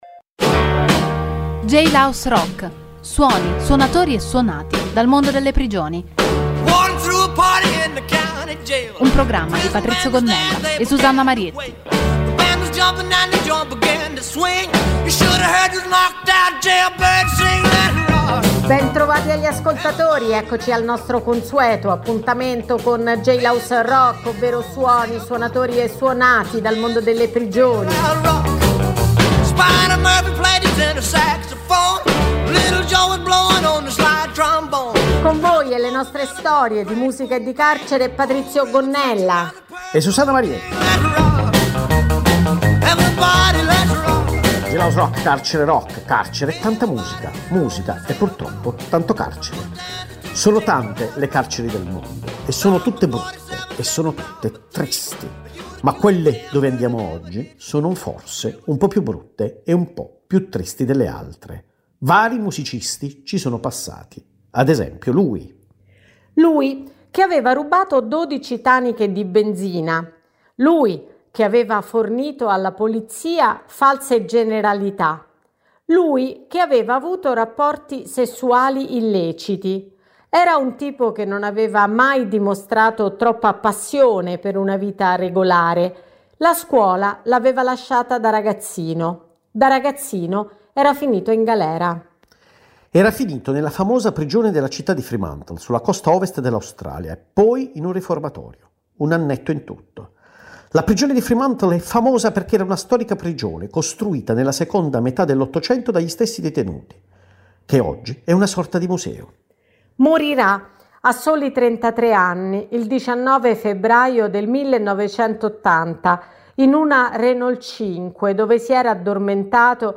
il programma include storie e suoni dal mondo delle prigioni, con la partecipazione di detenuti dei carceri di Rebibbia e Bollate che realizzano un Giornale Radio dal Carcere e cover di artisti.